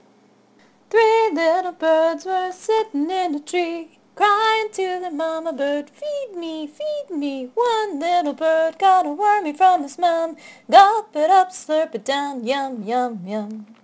I made this song up using the tune to Shortnin’ Bread (click on the triangle to hear the first verse):